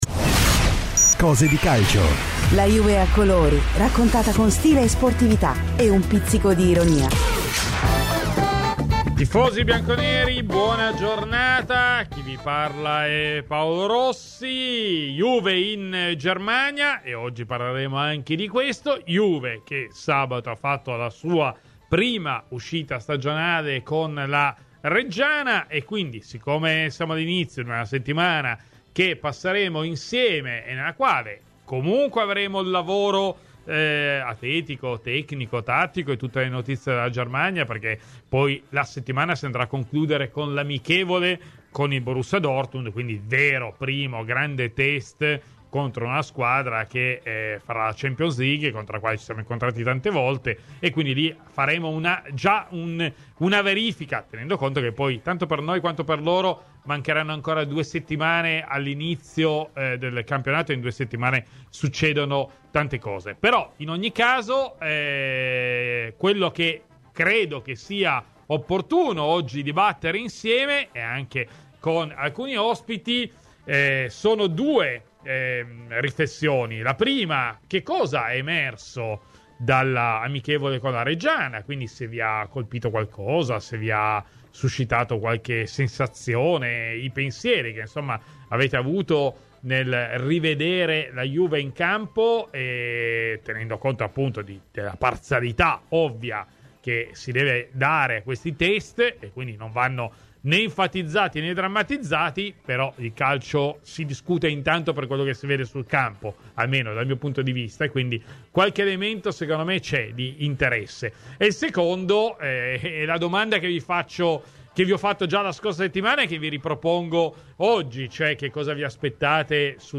In diretta dal ritiro della Juventus ad Herzogenaurach, in Germania